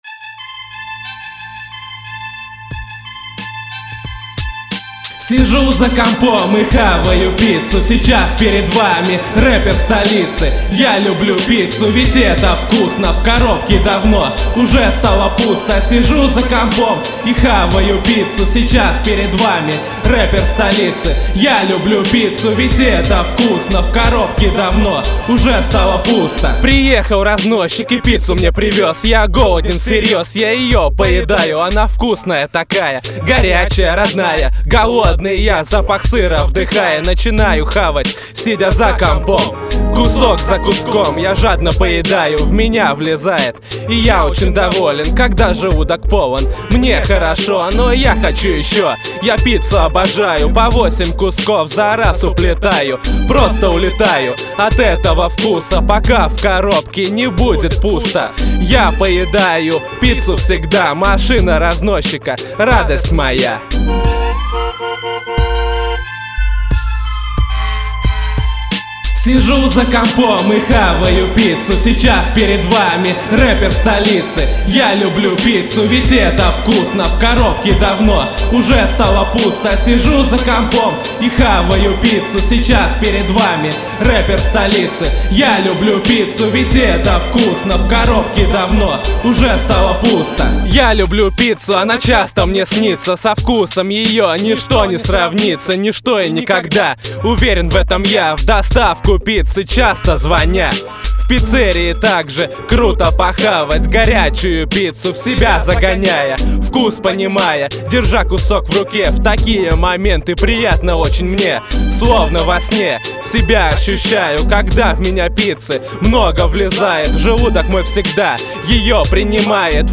(мой стебный трек. рэп читаю. т.ч. этот текст есть в муз. файле. прикрепил его, качайте кому понравилось)